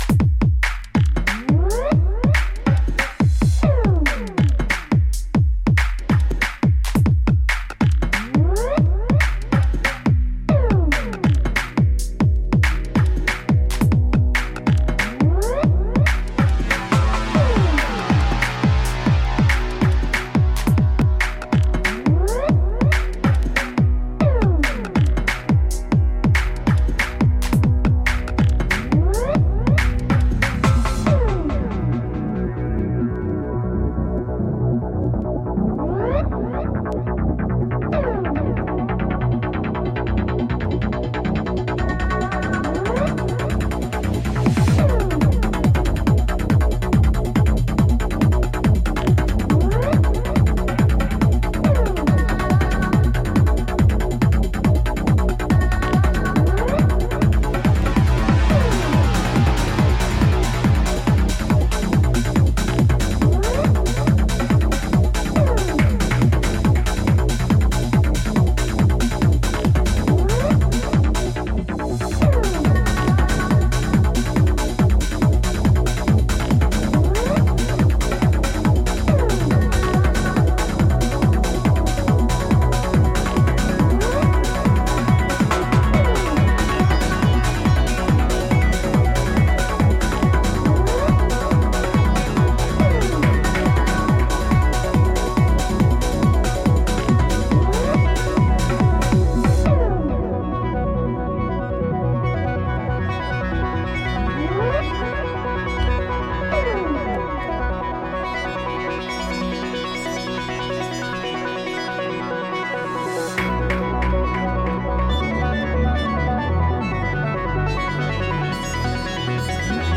UK Garage / Breaks